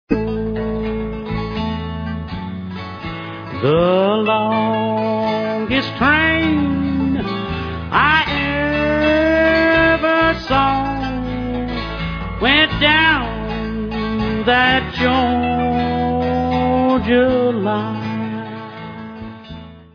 sledovat novinky v oddělení Rock/Bluegrass